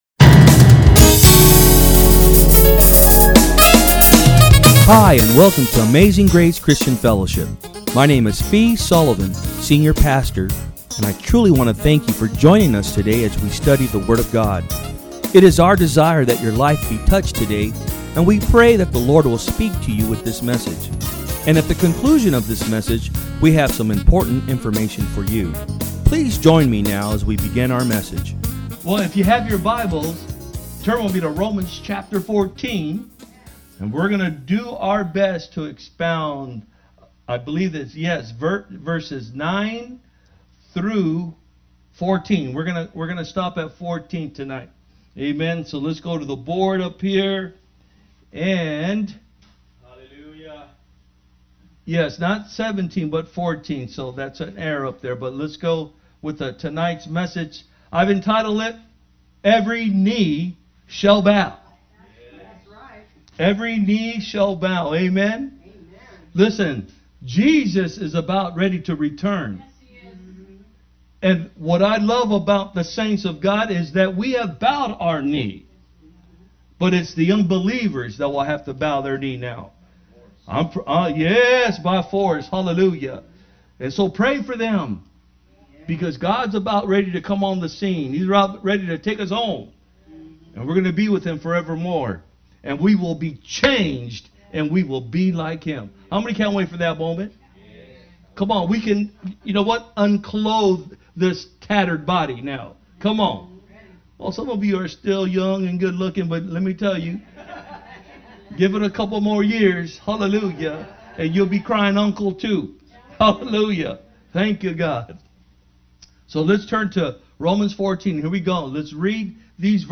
Sermons
From Service: "Wednesday Pm"